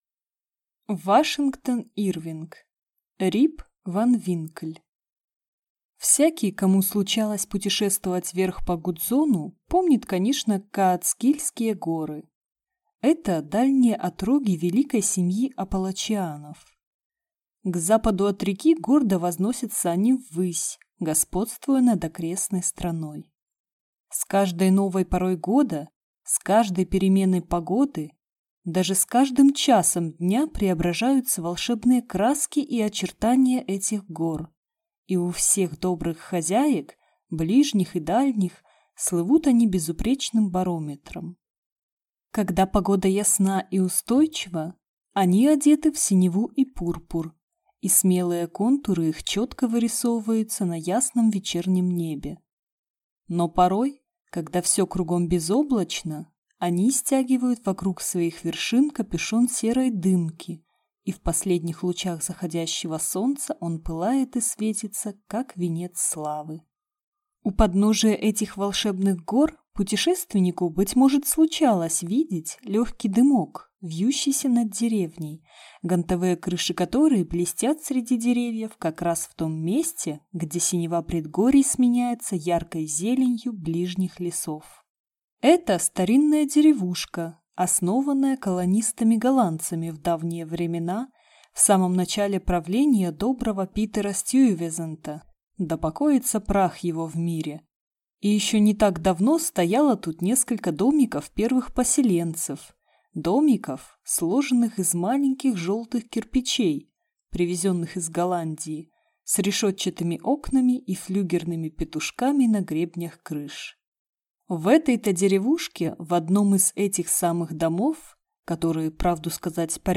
Аудиокнига Рип Ван Винкль | Библиотека аудиокниг